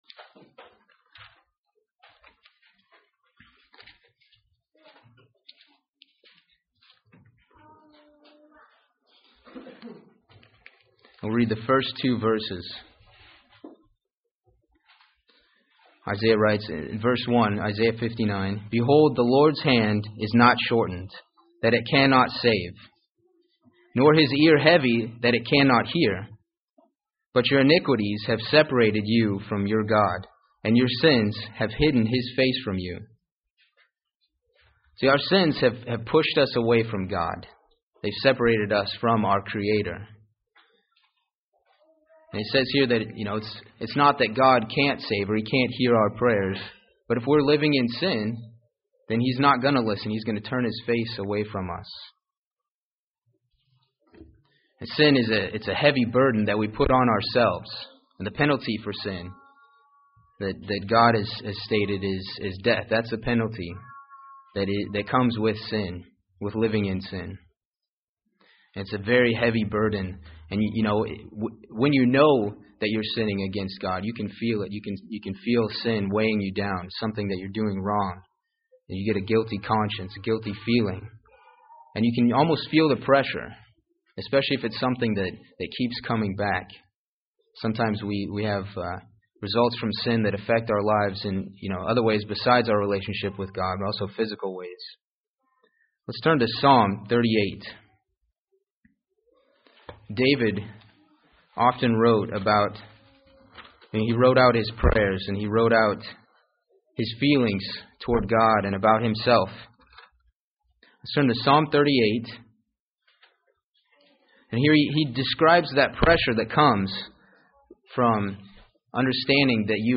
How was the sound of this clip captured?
Given in Gadsden, AL Huntsville, AL